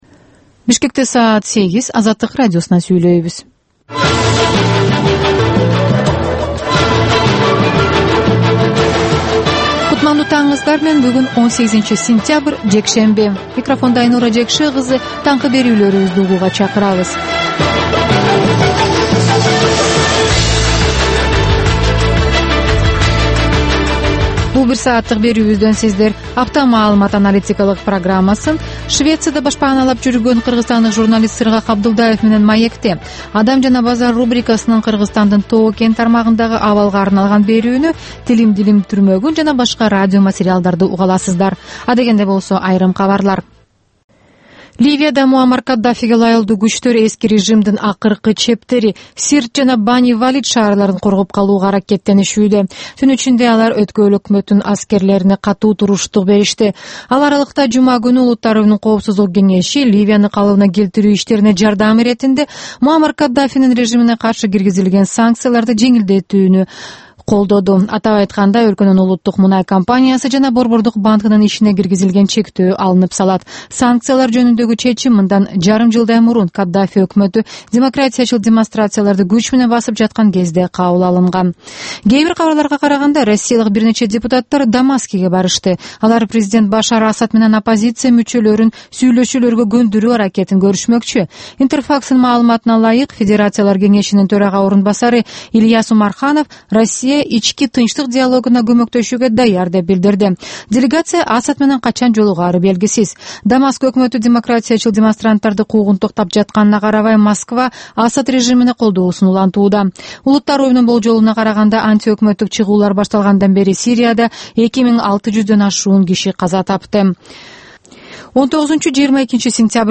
Азаттыктын кабарлары